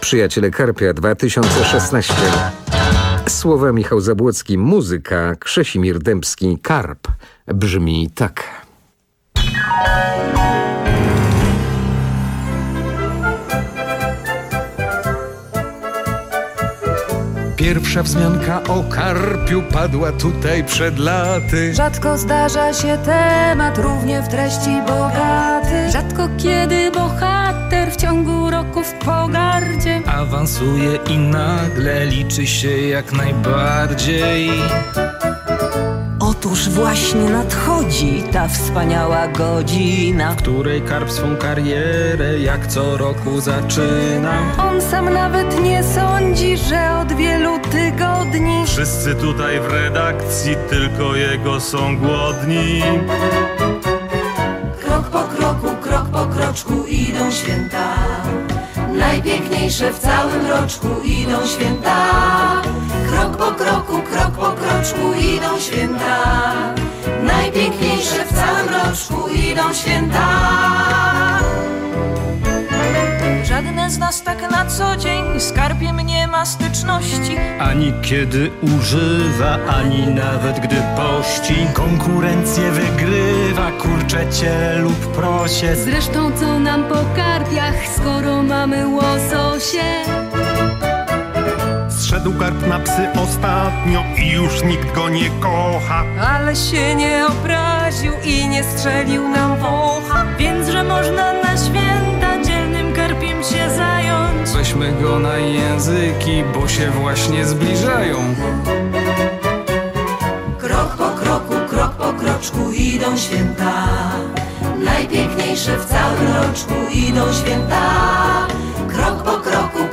Mam zapiętą do tego antenę typu DIPOL na samym szczycie bloku, a odbiór jest fatalny - brzmi to tak. Normalny tuner na tej antenie odbiera wzorowo wszystkie możliwe stacje bez szumu.